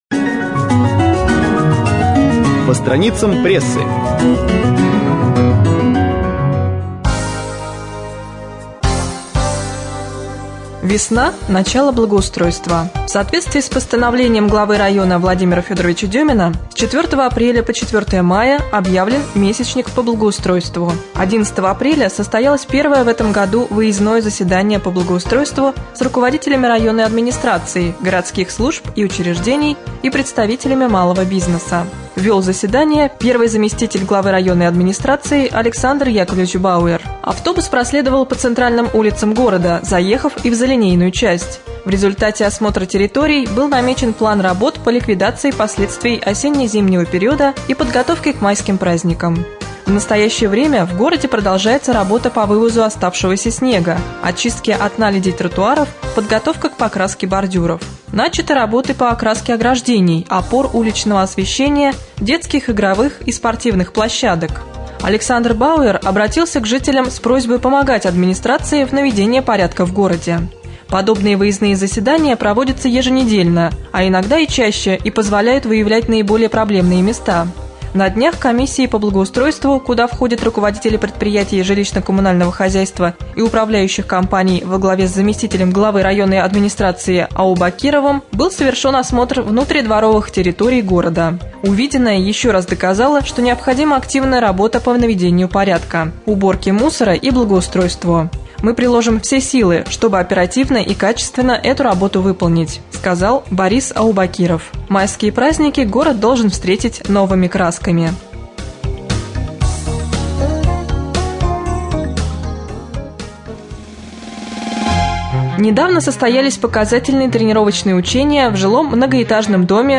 23.04.2013г. в эфире раменского радио - РамМедиа - Раменский муниципальный округ - Раменское